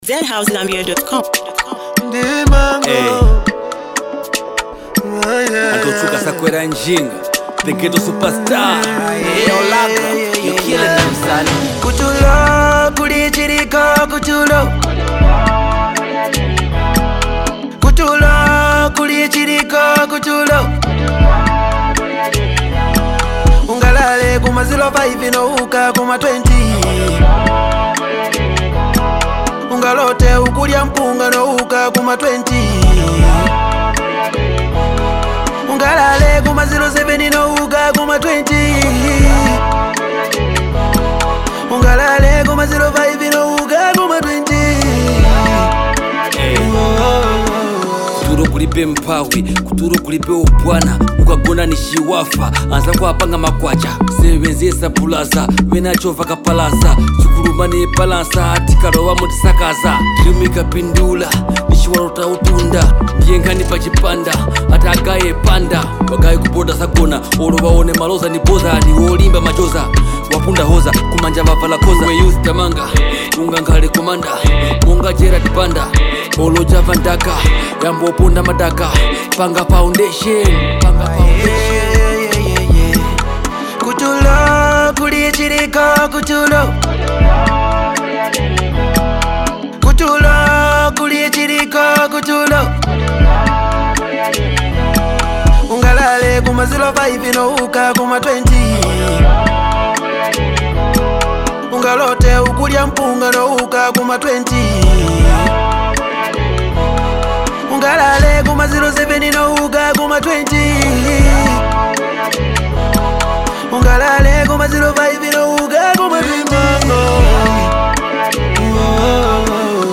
the track delivers energetic vibes, catchy melodies